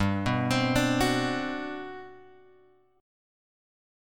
GM11 chord {3 2 x 5 3 2} chord